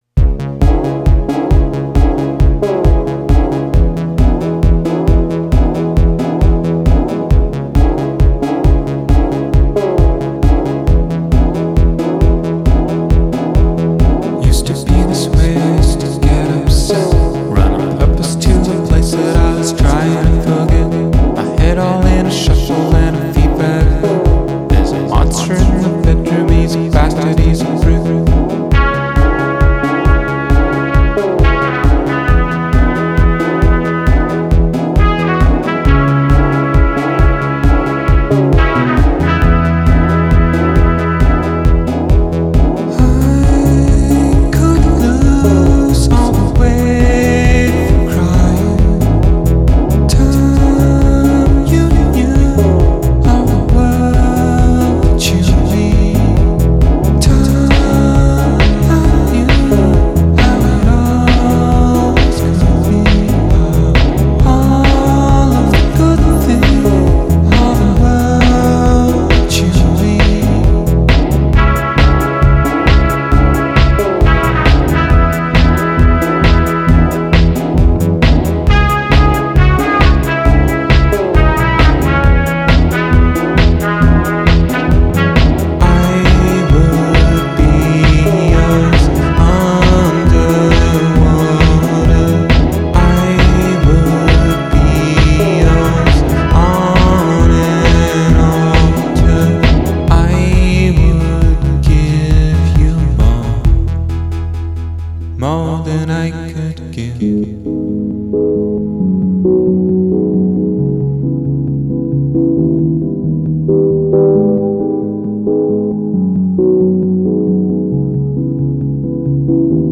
electro pop